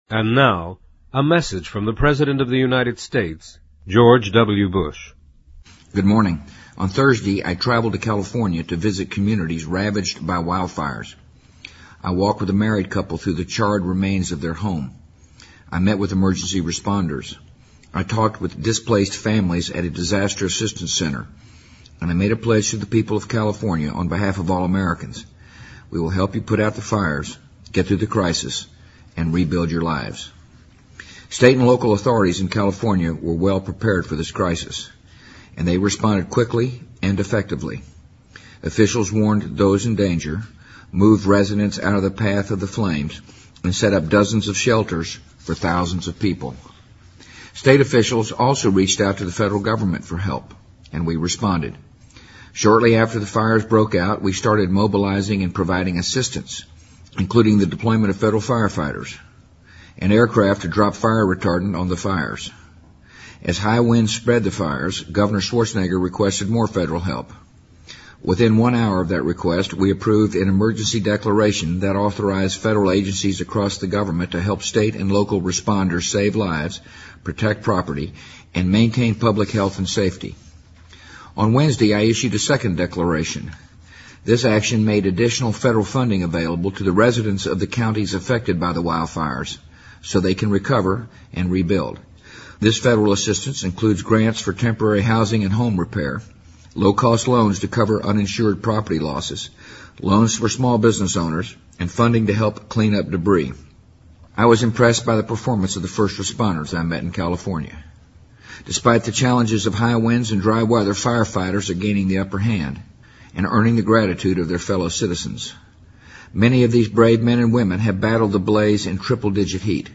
【美国总统电台演说】2007-10-27 听力文件下载—在线英语听力室